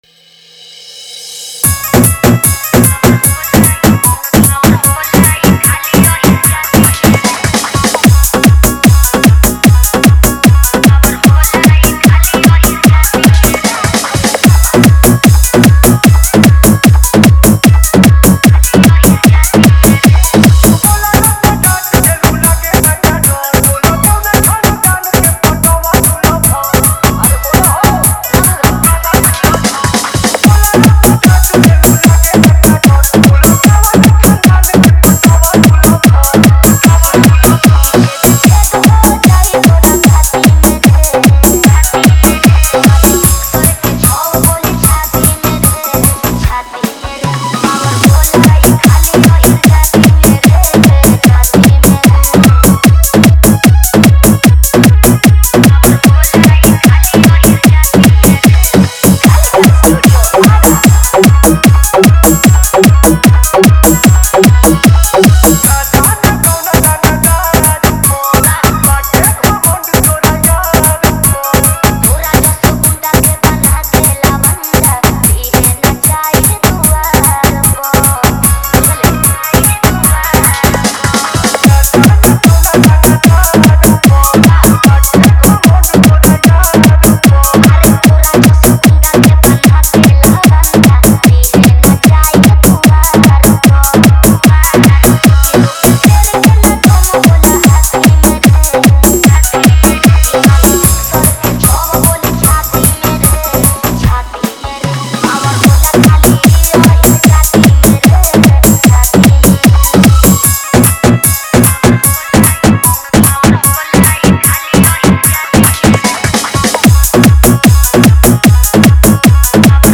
Category:  Bhojpuri Dj Remix